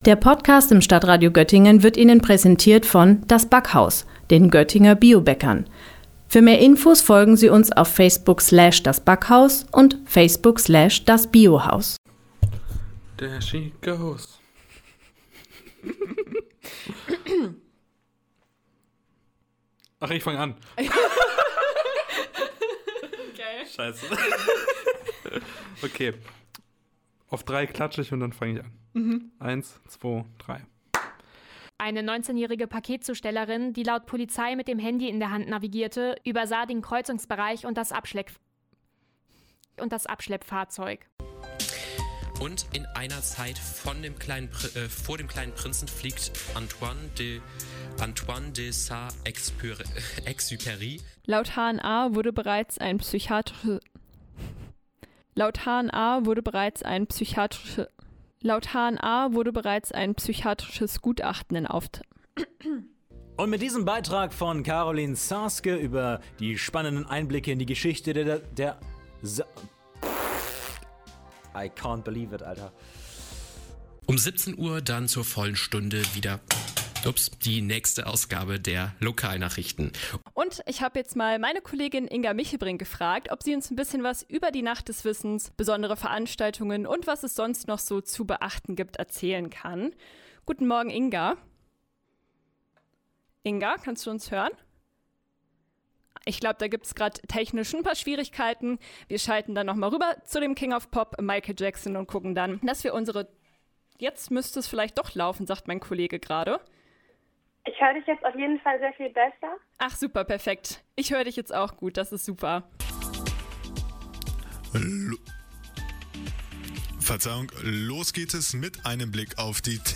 Normalerweise versuchen wir das gut zu verstecken, aber hin und wieder sind dann doch die Stimmbänder überstrapaziert, die Technik streikt oder man wird unverhofft mit Zungenbrechern konfrontiert. Was man so alles bei uns (ungeplant!) im Programm hören konnte – oder aber auch zum Teil noch rechtzeitig rausschneiden konnte – haben wir Ihnen in einer kleinen Auswahl zusammengestellt.